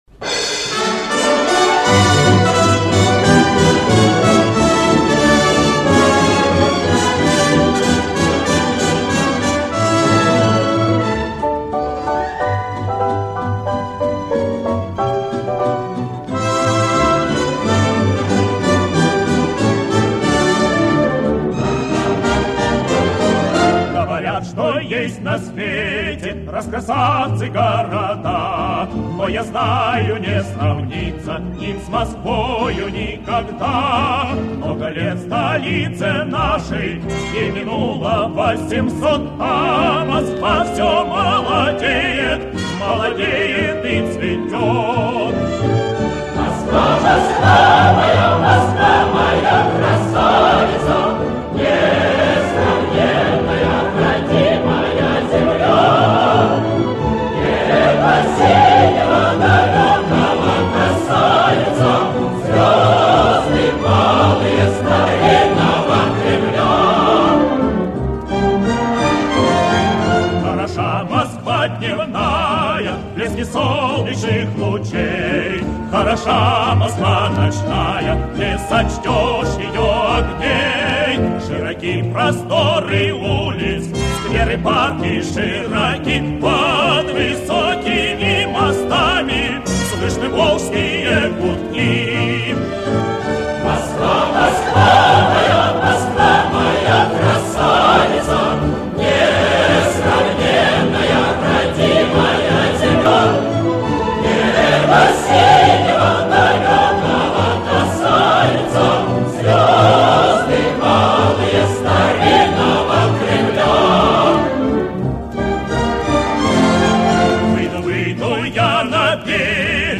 Помню,что ее исполнял мужской квинтет и хор.